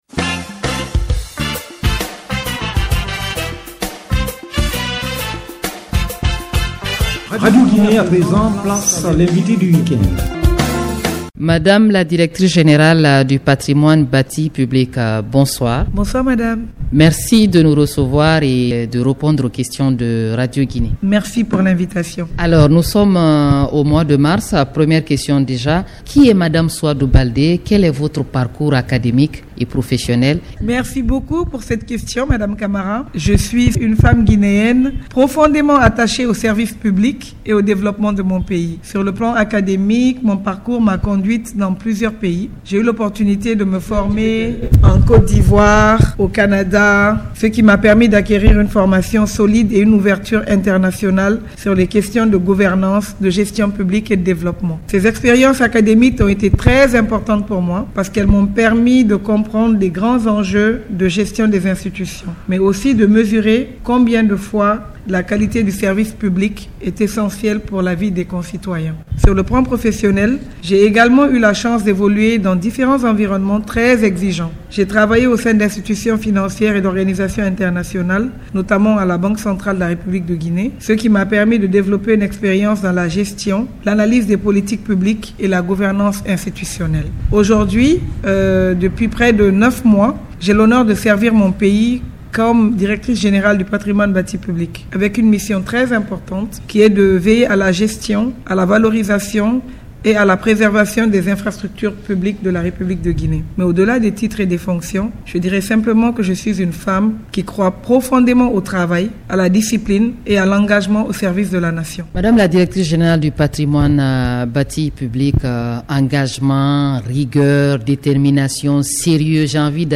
« L’invité du week-end » reçoit pour vous aujourd’hui la Directrice Générale du Patrimoine Bâti, Mme Souadou BALDÉ c’est au compte du mois de la femme guinéenne.